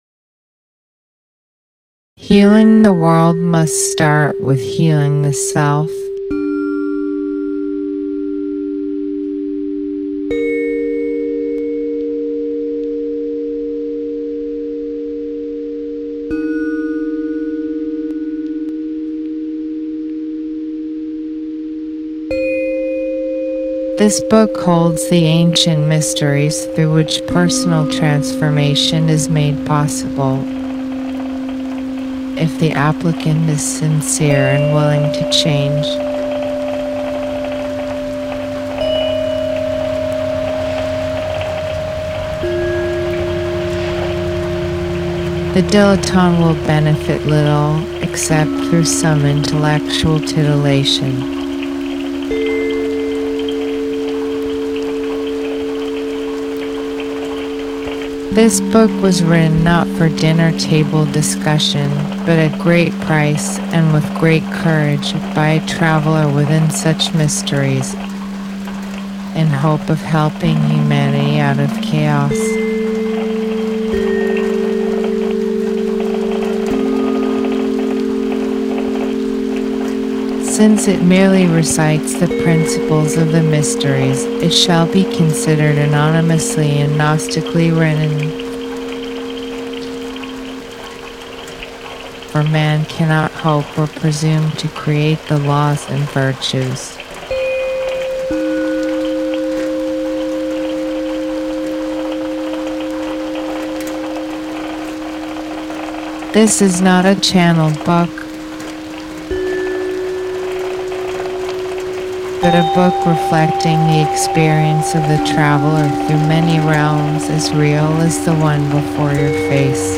Created while in residence at Wave Farm in Fall 2022
radio work